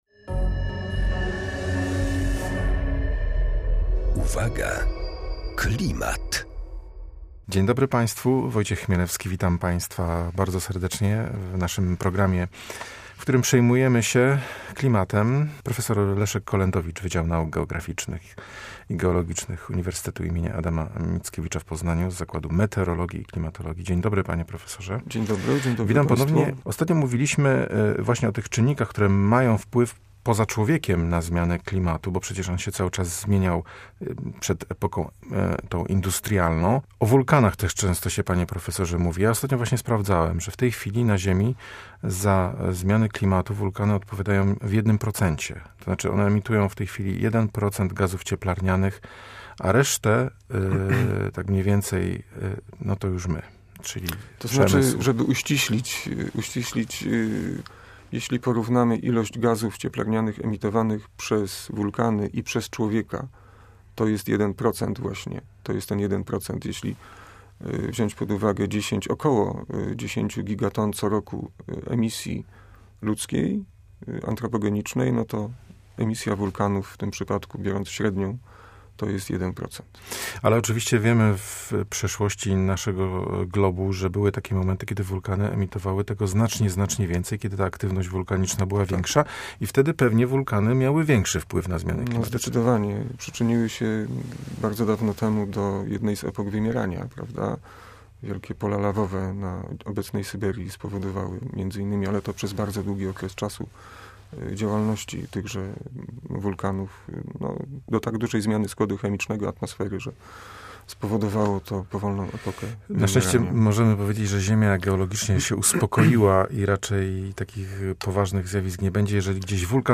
Na rozmowę